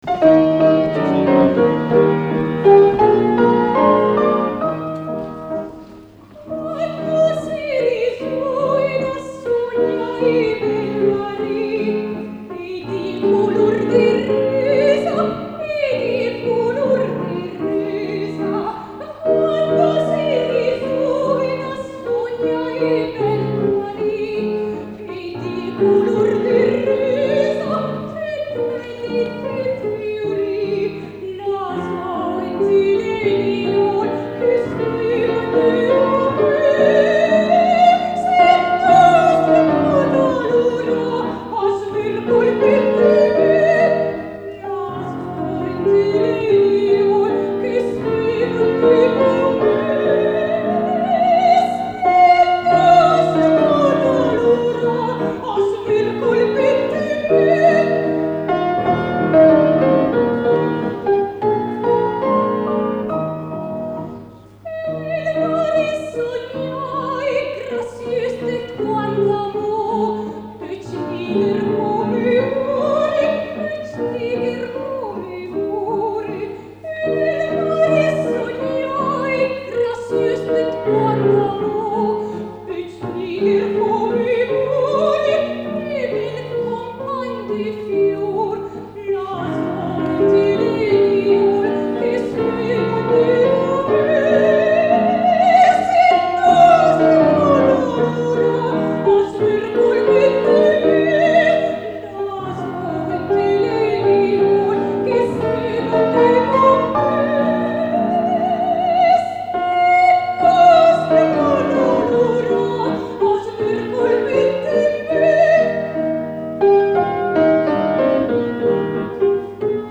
Sempre nel 1984 per la manifestazione CULTURA DOVE, cura lo spettacolo “Romanze popolari lombarde” (ascolta- in versione filologica- quelle bergamasche musicate da Alessandro Ferrari Paris nel 1892: Gigì te spuseró,